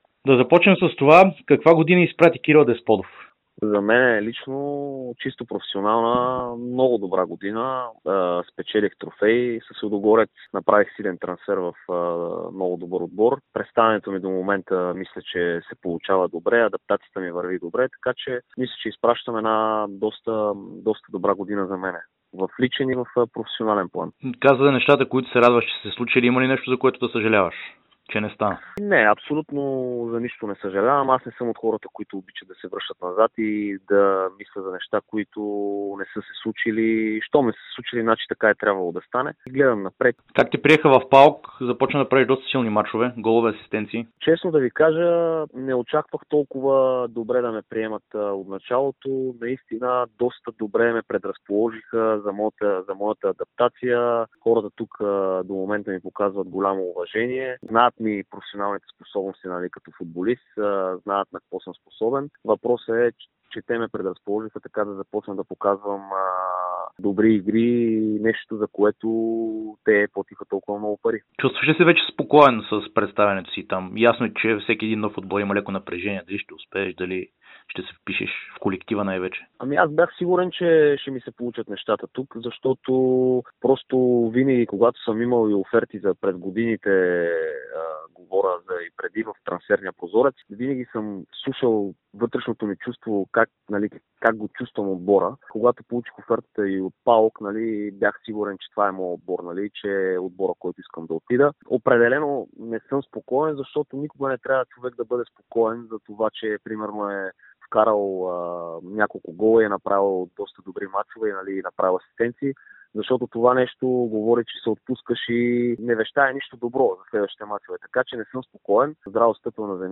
Нападателят на ПАОК и националния отбор по футбол Кирил Десподов даде специално интервю за Дарик радио и dsport след добрите изяви в последните мачове в гръцкото първенство.